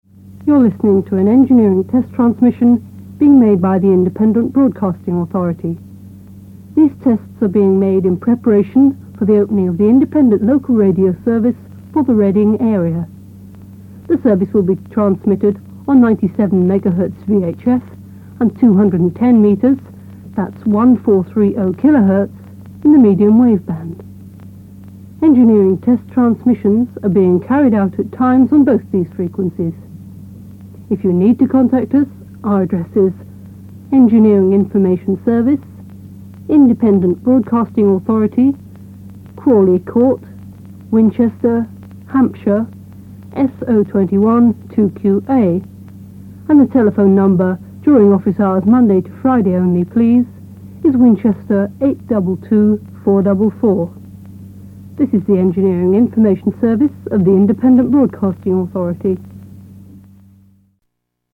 Here, hear the test transmissions from Radio 210 (Thames Valley Broadcasting), later 2-Ten FM and now Heart.